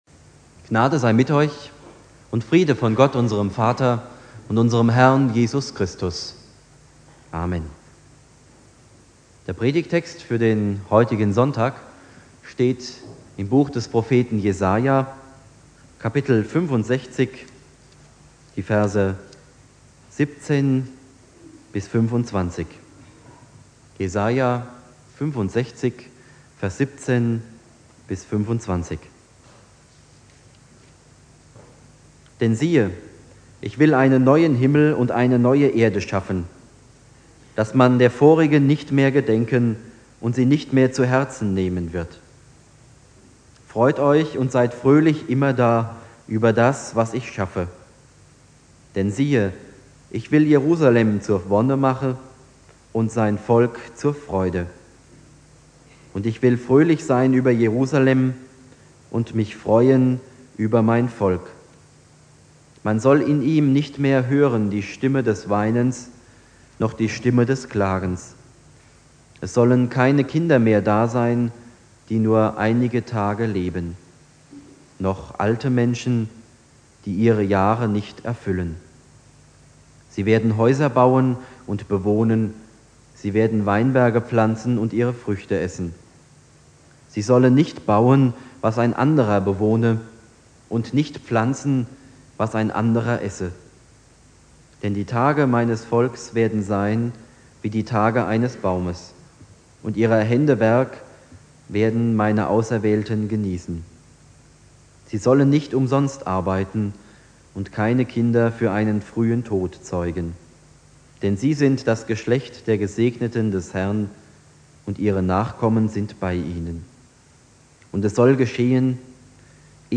Predigt
Ewigkeitssonntag Prediger